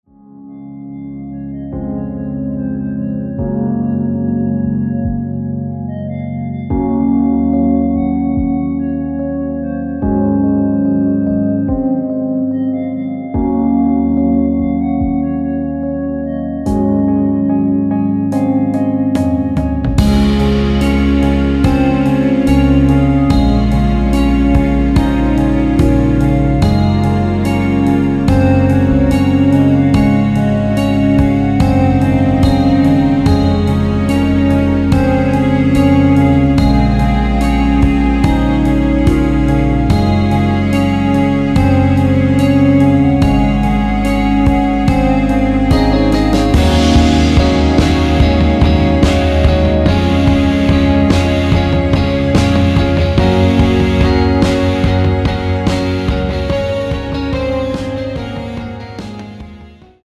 원곡 6분1초에서 4분 41초로 짧게 편곡된 MR입니다.
원키에서(-1)내린 멜로디 포함된(1절앞+후렴)으로 진행되는 MR입니다.
Db
앞부분30초, 뒷부분30초씩 편집해서 올려 드리고 있습니다.
중간에 음이 끈어지고 다시 나오는 이유는